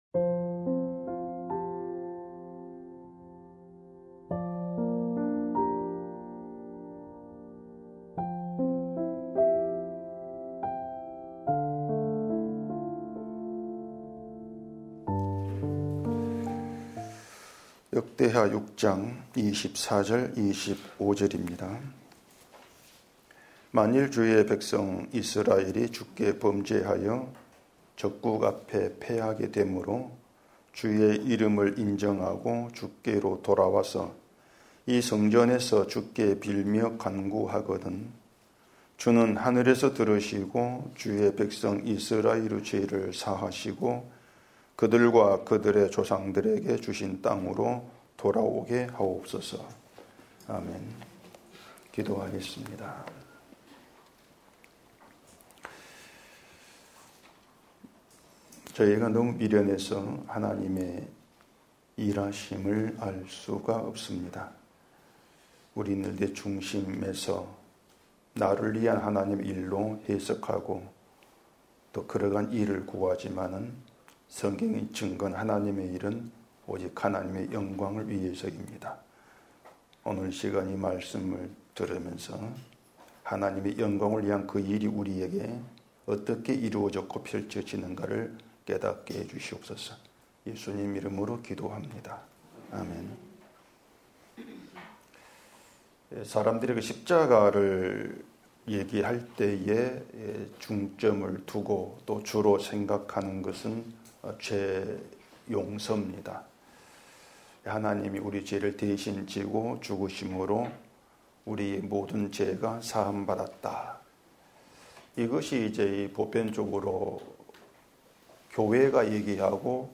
주일오전 - (25강) 죄를 사하시고